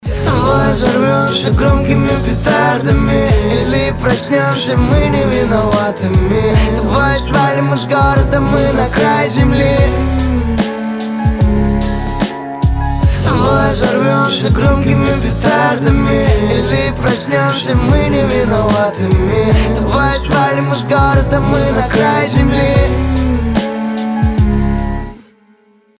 • Качество: 320, Stereo
лирика
Хип-хоп
спокойные